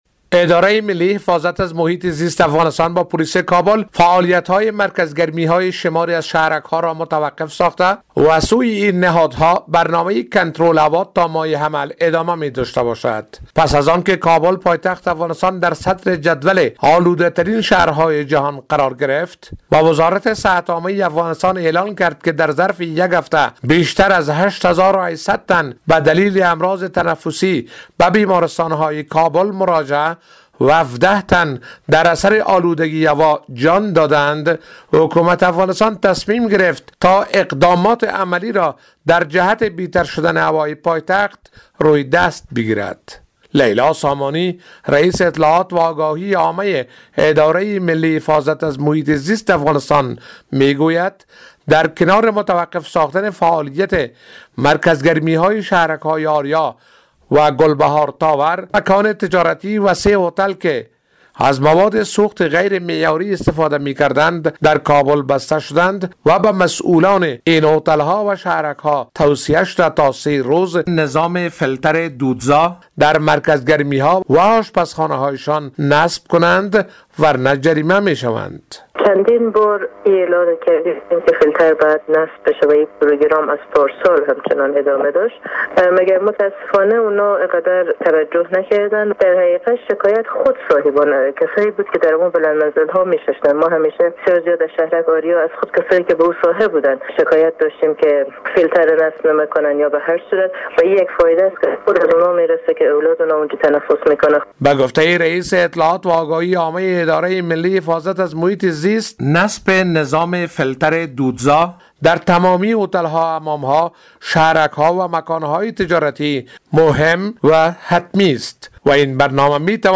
به گزارش خبرنگار رادیو دری، دولت افغانستان با پنج صنف آلوده کننده هوا در کابل که تخلفات زیست محیطی آن ها تثبیت شده بود برخورد کردند.